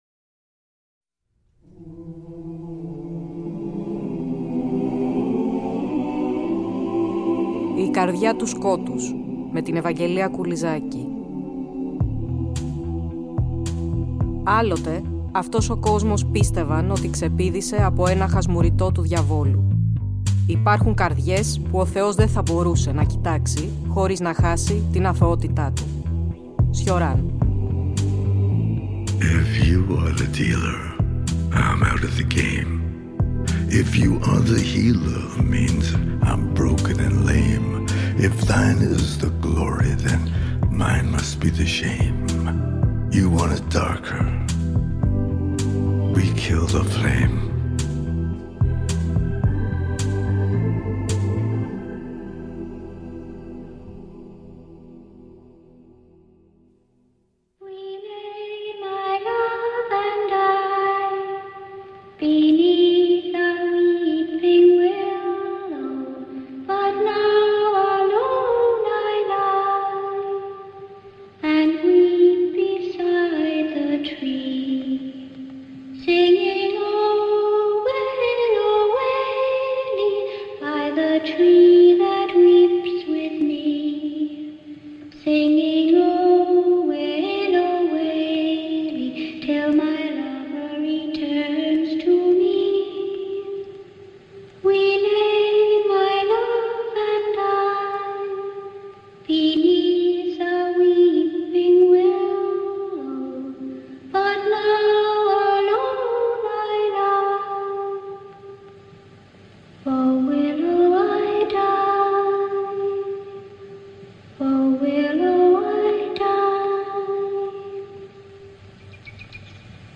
» Ακούμε, μεταξύ άλλων , αποσπάσματα από την όπερα «Το Στρίψιμο της Βίδας» του Benjamin Britten .